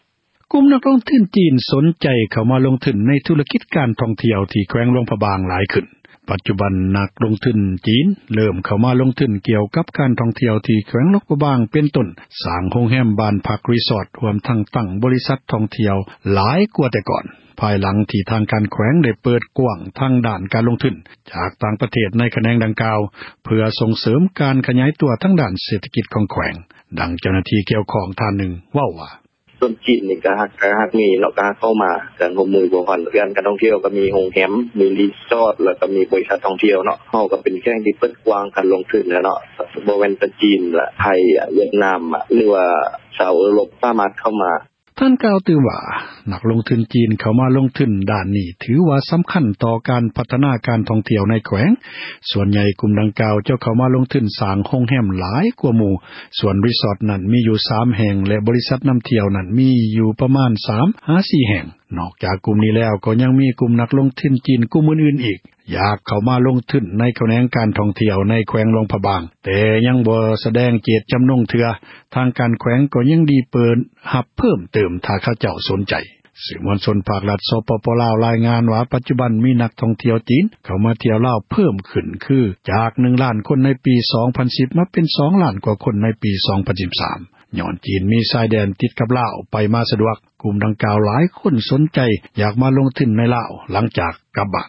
ປັດຈຸບັນ ນັກ ທຸຣະກິດ ຈີນ ເລິ້ມ ເຂົ້າມາ ລົງທຶນ ຂແນງການ ທ່ອງທ່ຽວ ທີ່ ແຂວງ ຫຼວງພຣະບາງ ເປັນຕົ້ນ ສ້າງ ໂຮມແຮມ ບ້ານພັກ ຣິສອດ ຈັດຕັ້ງ ບໍຣິສັດ ທ່ອງທ່ຽວ ຫລາຍຂຶ້ນ ກວ່າ ແຕ່ກອ່ນ ພາຍຫລັງ ທີ່ ທາງການ ແຂວງ ໄດ້ ເປີດກວ້າງ ການ ລົງທຶນ ຈາກ ຕ່າງ ປະເທດ ໃນຂແນງ ດັ່ງກ່າວ ເພື່ອ ສົ່ງເສີມ ການຂຍາຍໂຕ ດ້ານ ເສຖກິດ ຂອງ ແຂວງ. ດັ່ງ ເຈົ້າຫນ້າທີ່ ກ່ຽວຂ້ອງ ເວົ້າວ່າ: